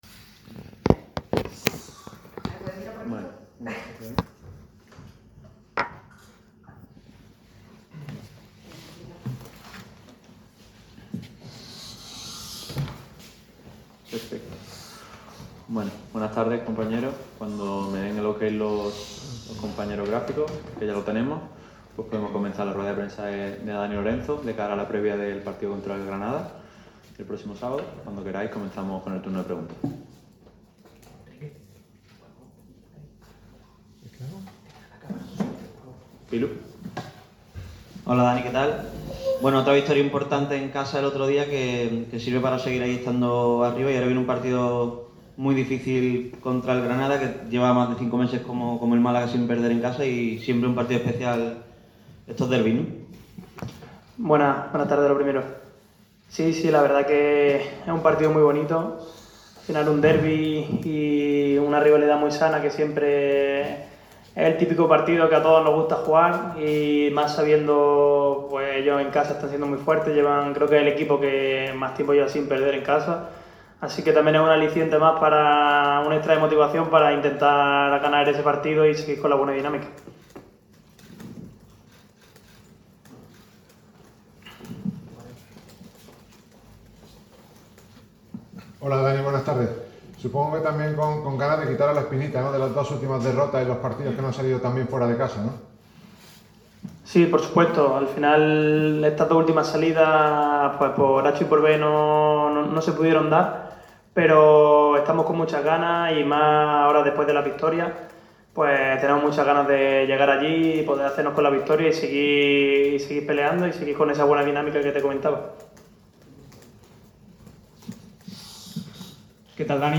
Rueda de prensa íntegra